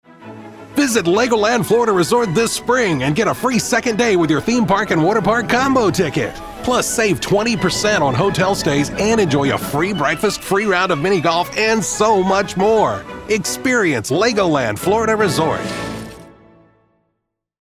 Legoland Resort - Playful, Young, Enthusiastic